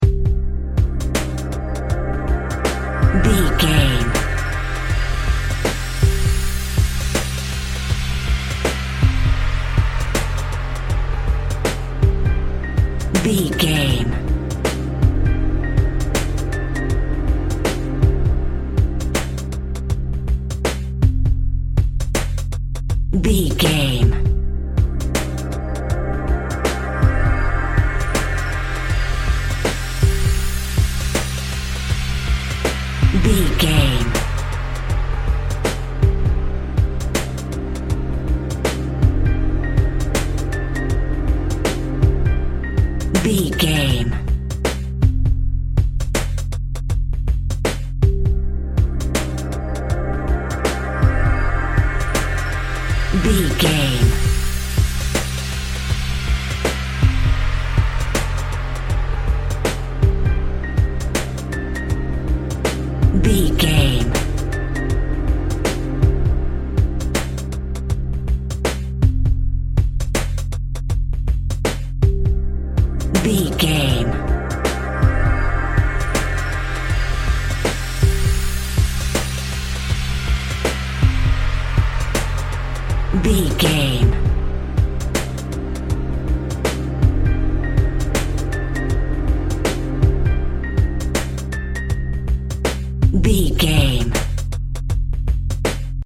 Ionian/Major
90s
instrumental music
synths
synth lead
synth bass
synth drums